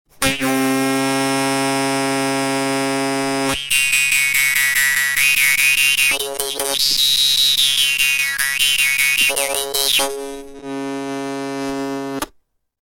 Maultrommel Dan Moi Mini
Bambus & Messing, Vietnam
Im Klang ist sie höher und lauter.
Die vietnamesischen Maultrommeln zeichnen sich durch ihre einfache Spielbarkeit, ihren schönen, obertonreichen Klang und den günstigen Preis aus.
Die Dan Moi kann langsam-meditativ oder schnell-dynamisch gespielt werden. Dabei erinnert ihr Sound an elektronische Klänge analoger Synthesizer – ganz ohne Technik.